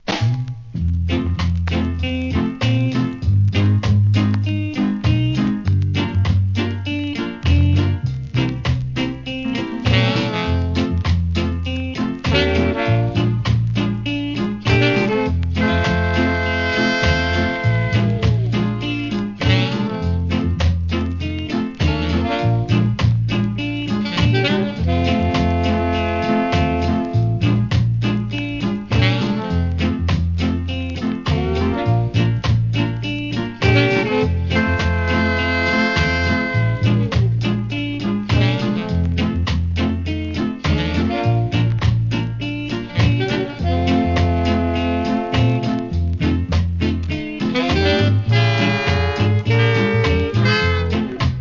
REGGAE
ムーディーなROSKSTEADY♪